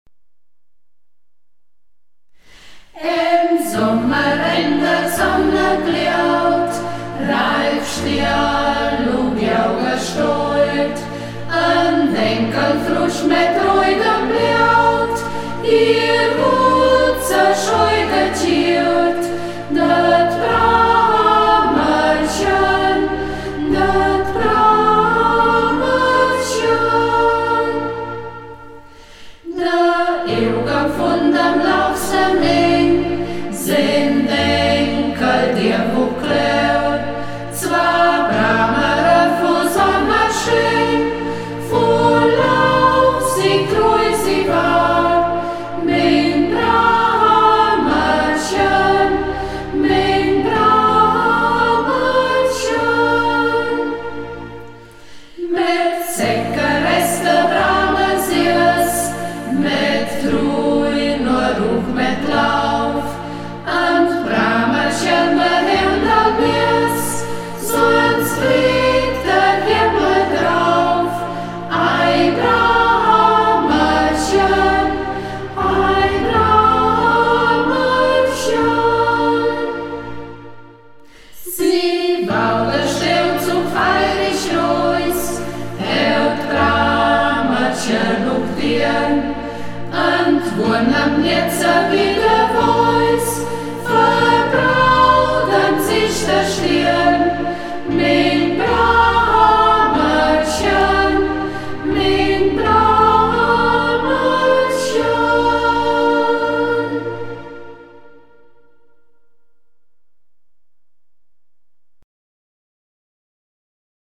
Lieder in Burgberger Mundart, gesungen von der Burgberger Singgruppe